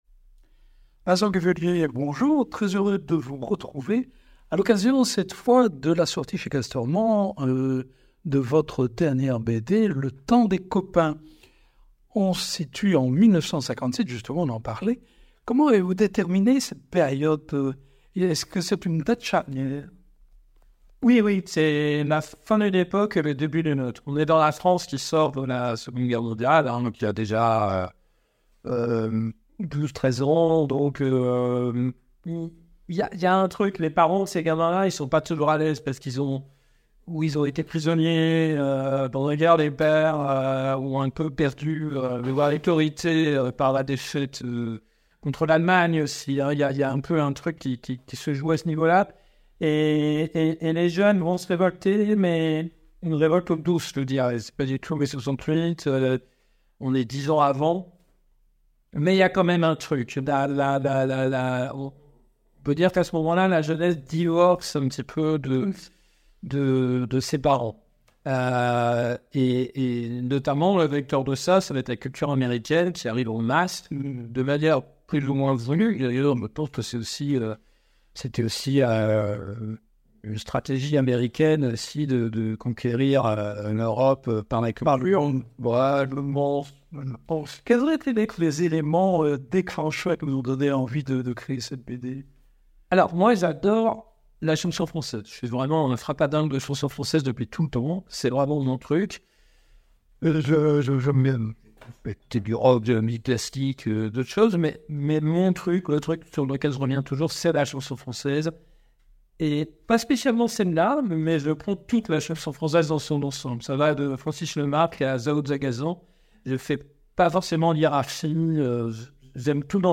C’est la genèse de ces mythes que Jeff Pourquié et Vincent Cuvellier vous racontent avec un zeste de nostalgie dans leur BD: « LE TEMPS DES COPAINS». Rencontre avec le scénariste.